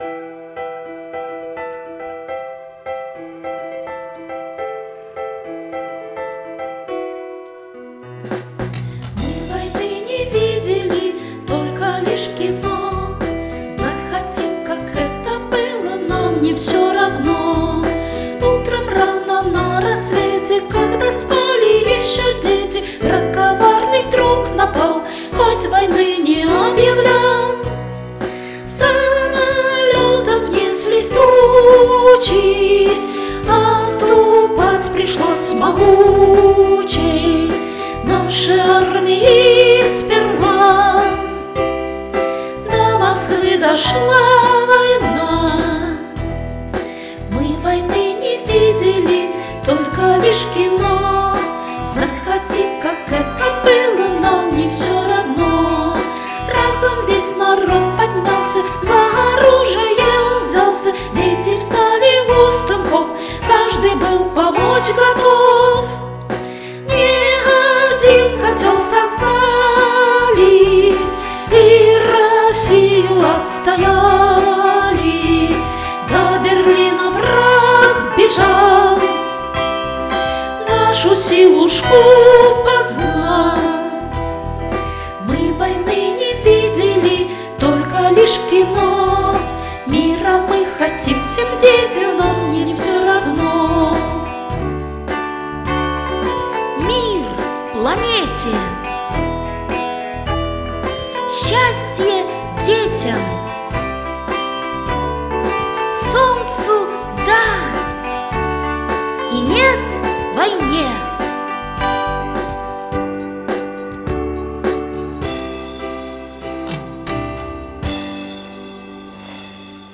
• Качество: Хорошее
• Жанр: Детские песни
🎶 Детские песни / Песни на праздник / День Победы🕊